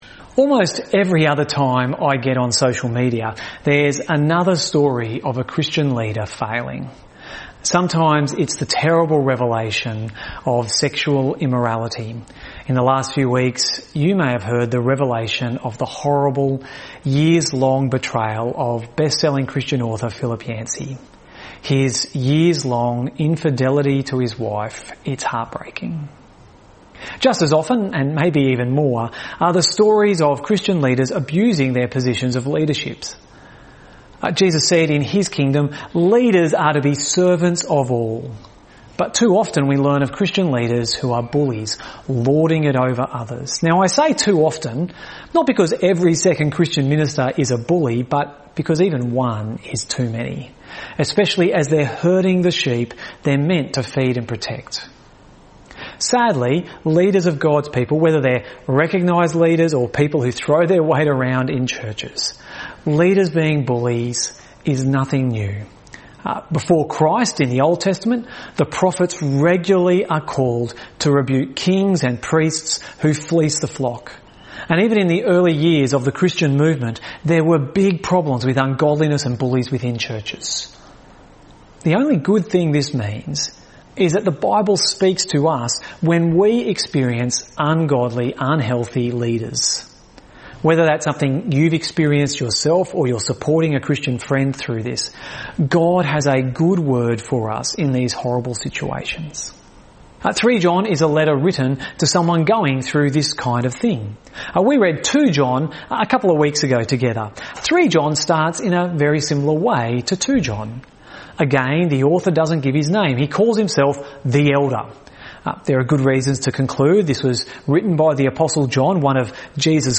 Note: this message was pre-recorded on video.